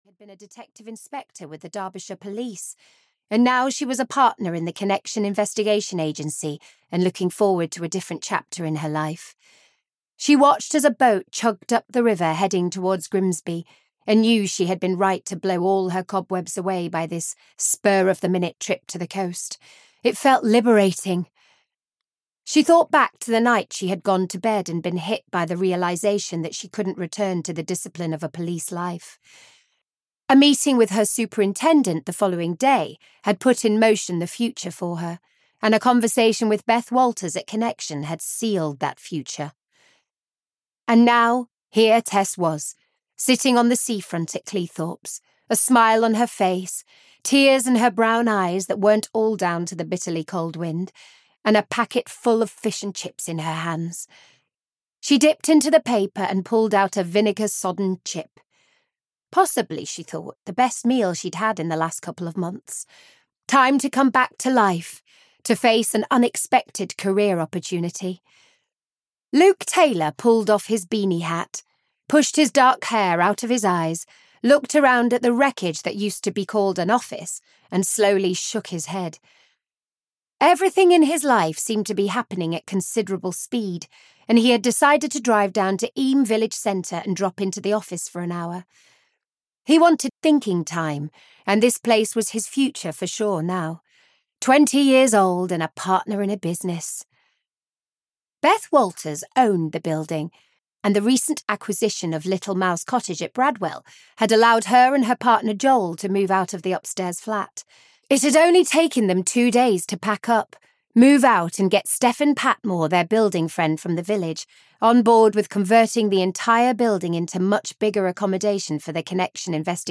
Blood Red (EN) audiokniha
Ukázka z knihy